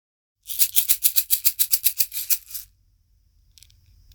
T字マラカス KARITSNA
マダガスカル産の竹でできたT字型マラカスです。中に小石や種が入っていて、シャカシャカとご機嫌なサウンドがします。もち手がしっかりして優しい音がするため、高齢者や福祉施設でも使われています。
素材： 竹 小石 種